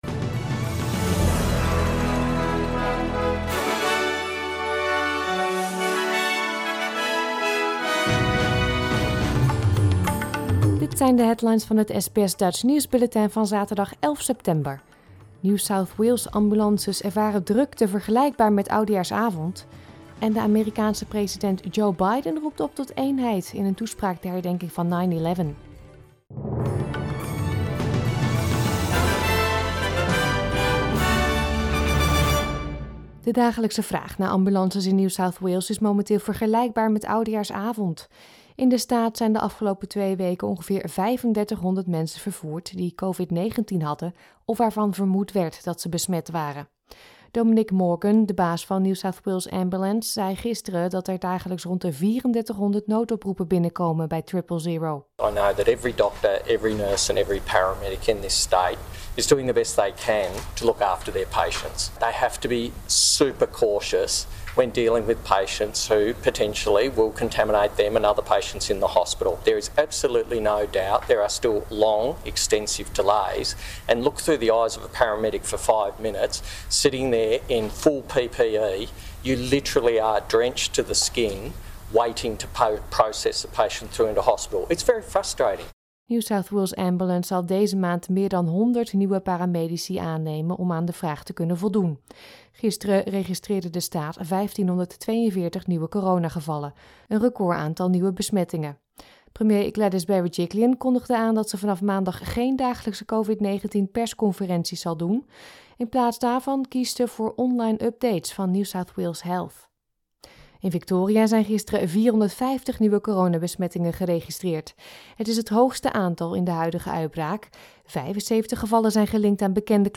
Nederlands/Australisch SBS Dutch nieuwsbulletin van zaterdag 11 september 2021